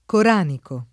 coranico [ kor # niko ]